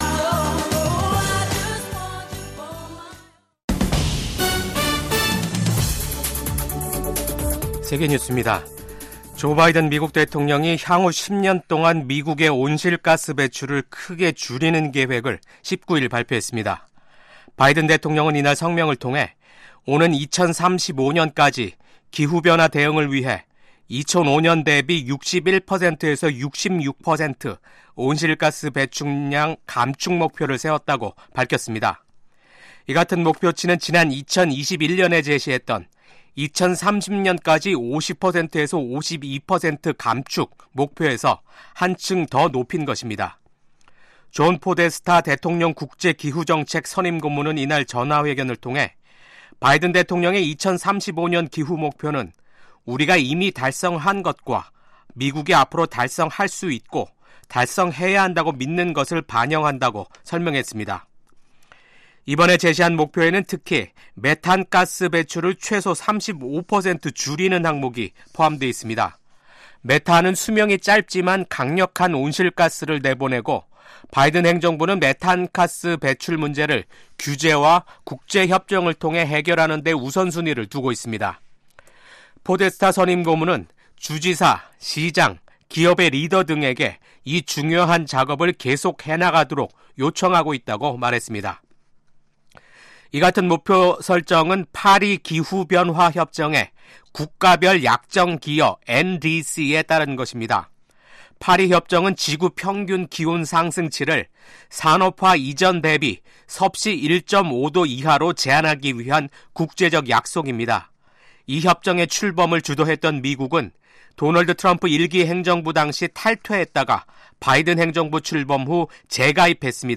VOA 한국어 아침 뉴스 프로그램 '워싱턴 뉴스 광장'입니다. 한국 국가정보원은 우크라이나 전쟁에 파견된 북한 군 병사들의 전사 사실을 확인했습니다. 미국과 한국 등 유엔 안보리 이사국들이 북한의 무기 개발과 러시아에 대한 병력 파병과 무기 제공을 강하게 규탄했습니다.